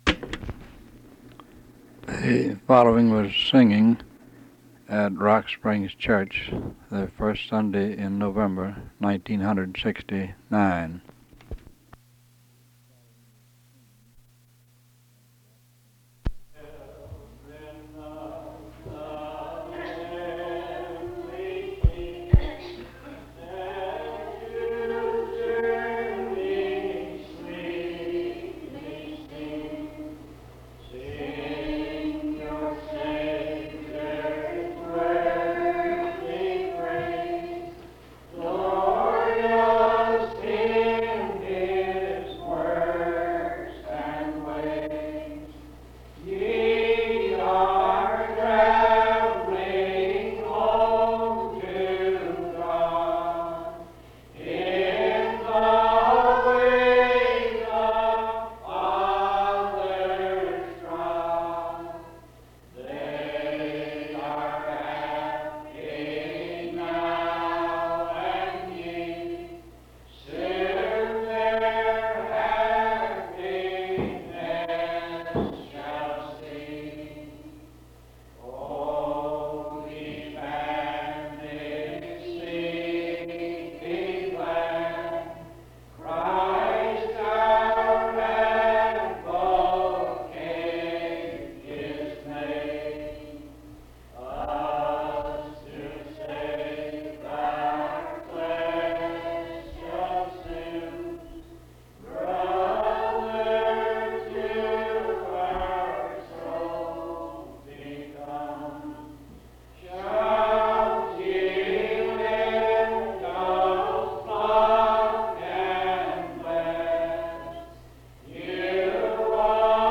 Clips of singing
Hymns Primitive Baptists